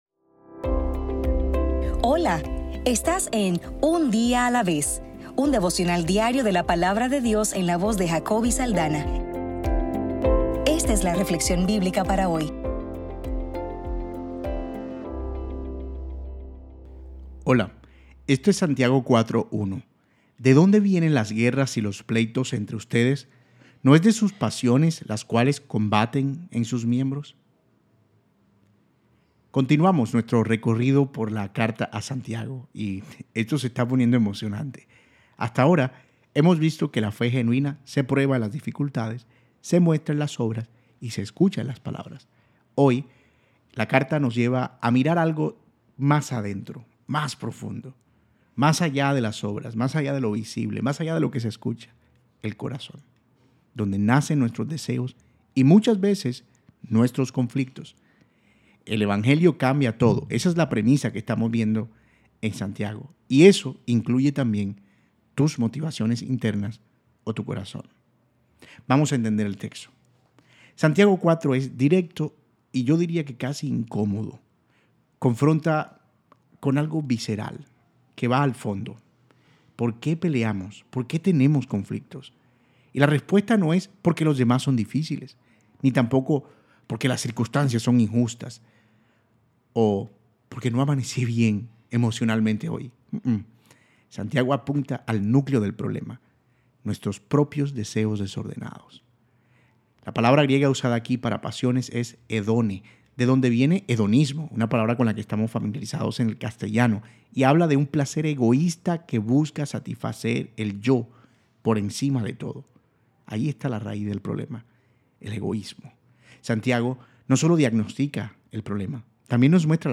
Devocional para el 12 de mayo